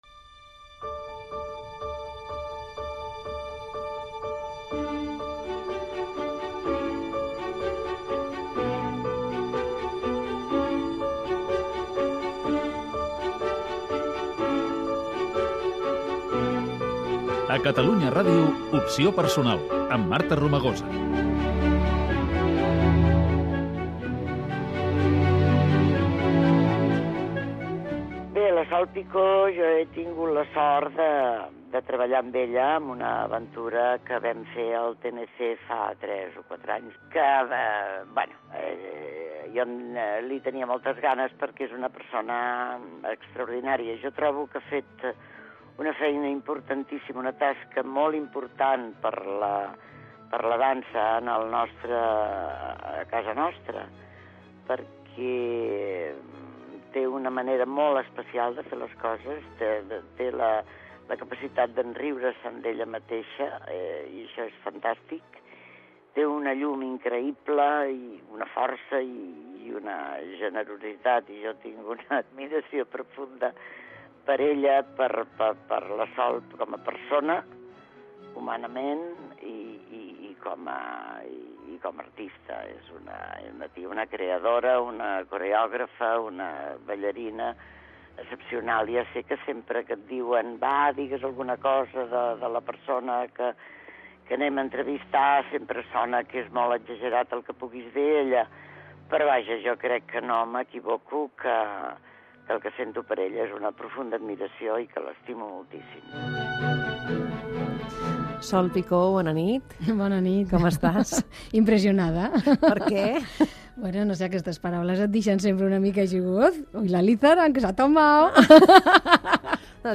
Careta del programa, opinió d'Anna Lizarán i entrevista a Sol Picó, ballarina i coreògraf: l'obra "El ball", feta al Teatre Nacional de Catalunya, la seva infantesa i els seus inicis a la dansa
Entreteniment